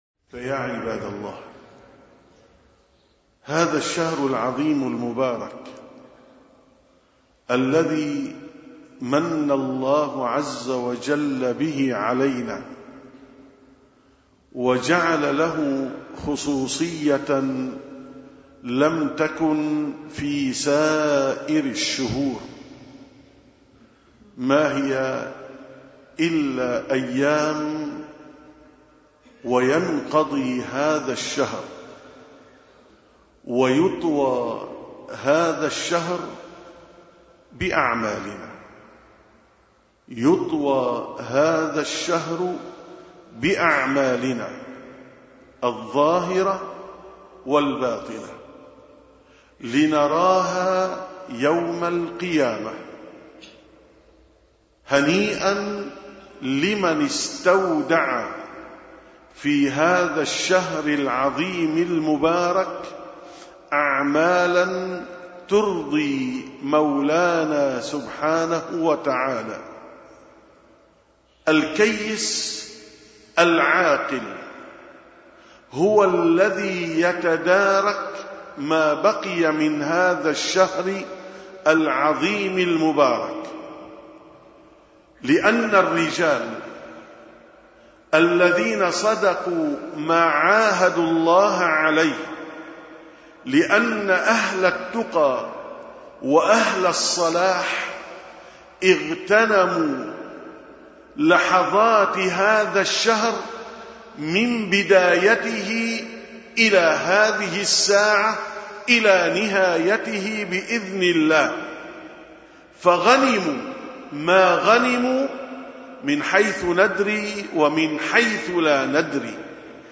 863ـ خطبة الجمعة: العشر الأخير تاج الليالي